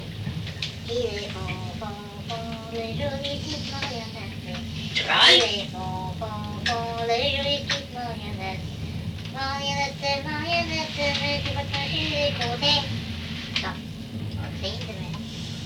Emplacement La Grand'Terre